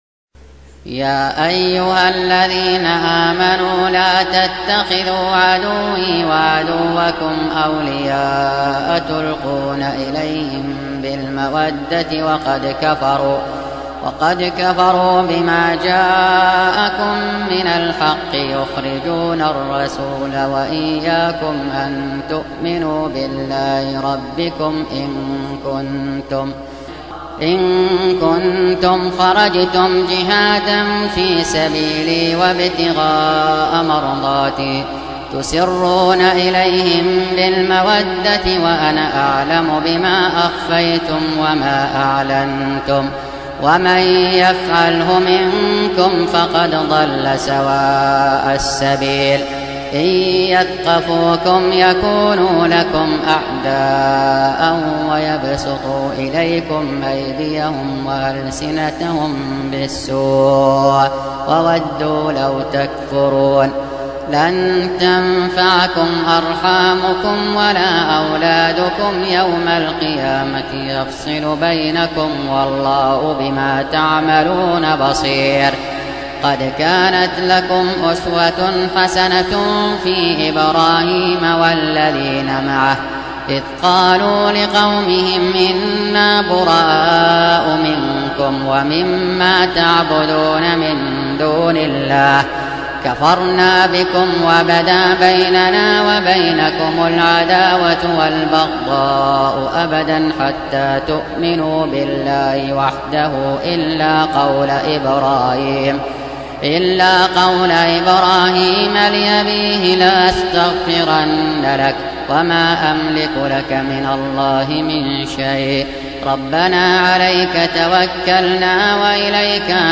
Surah Repeating تكرار السورة Download Surah حمّل السورة Reciting Murattalah Audio for 60. Surah Al-Mumtahinah سورة الممتحنة N.B *Surah Includes Al-Basmalah Reciters Sequents تتابع التلاوات Reciters Repeats تكرار التلاوات